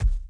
walk_dirt_h.wav